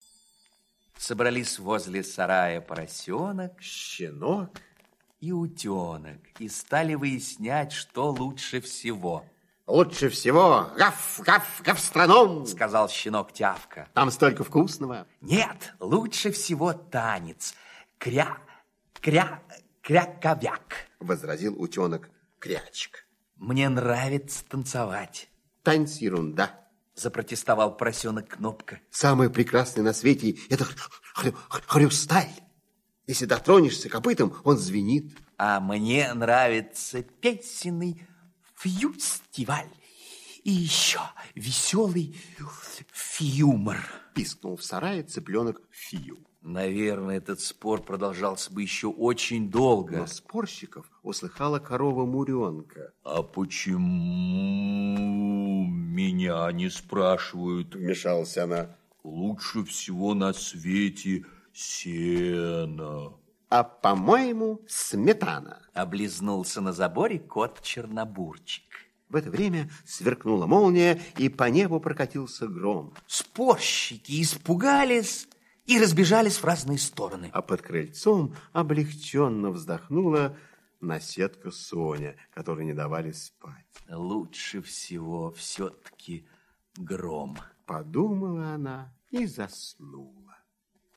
Слушайте Что лучше всего - аудиосказка Пляцковского М.С. Сказка про то, как поросенок, щенок и утенок и стали думать: "Что лучше всего?"